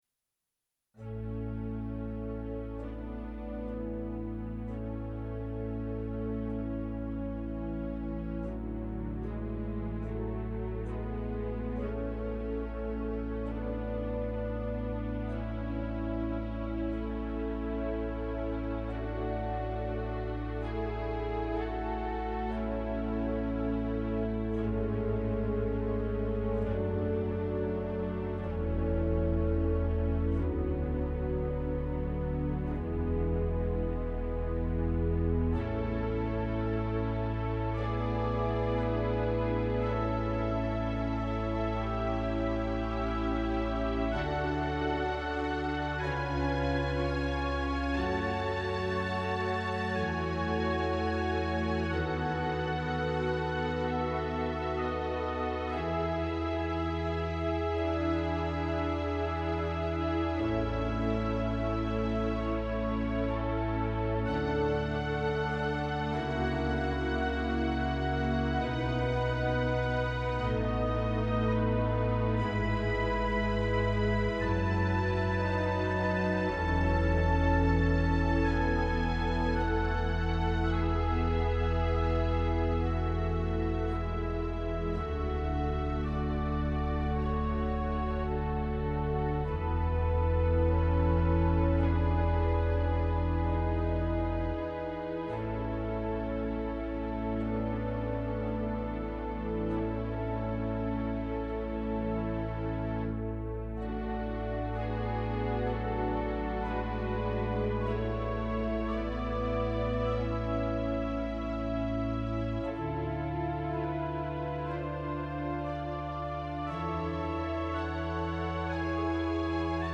Organ Meditations Audio Gallery
Peaceful works for quiet reflection